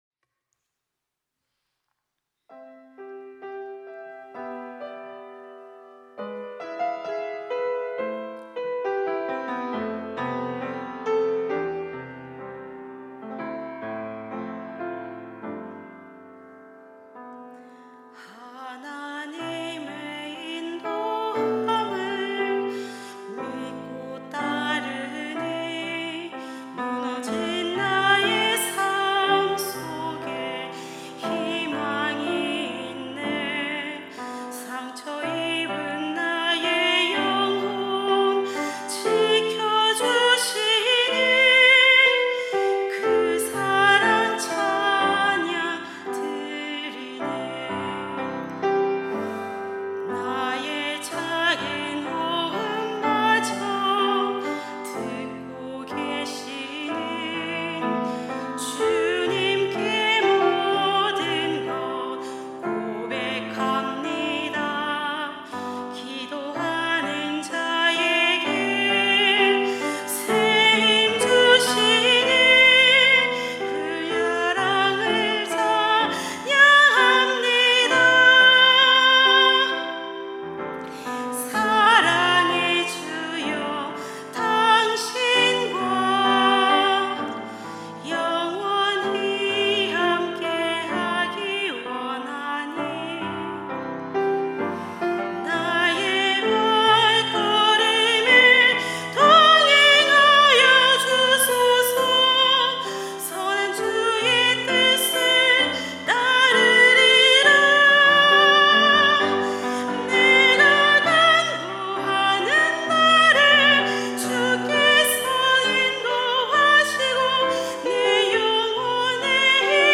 특송과 특주 - 내가 간구하는 날에